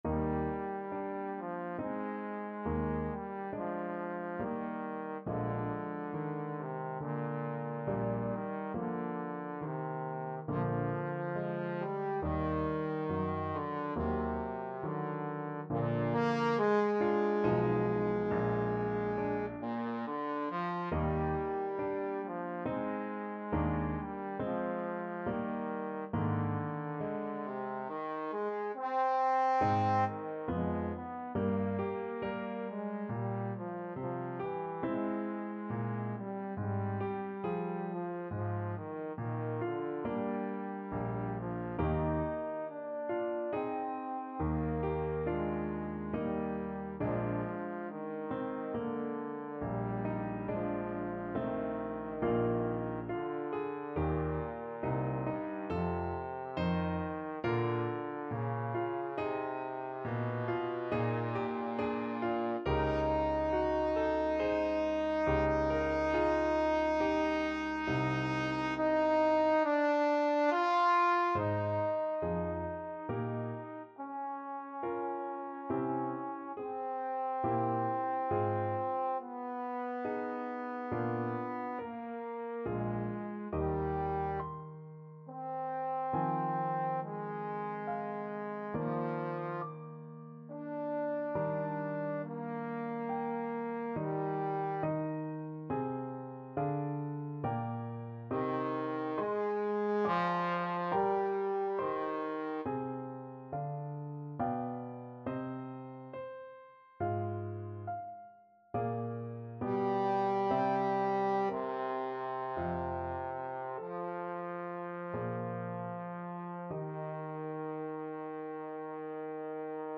Trombone
6/4 (View more 6/4 Music)
Andante =c.84 =69
Eb major (Sounding Pitch) (View more Eb major Music for Trombone )